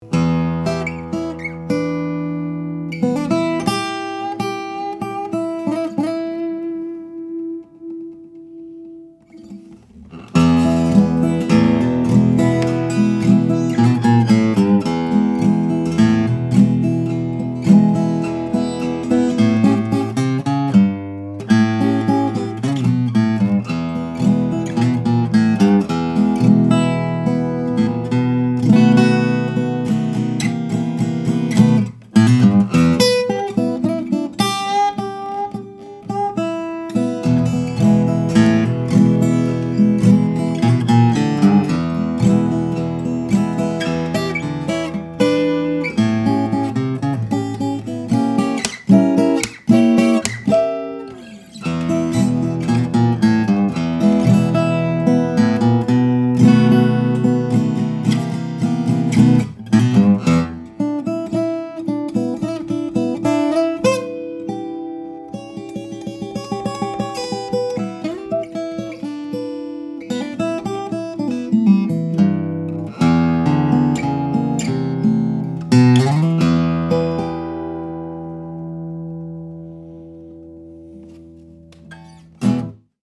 The 0 size is petite, but by no means quiet (the intervening decades having done some work on opening up the all-Mahogany body), and…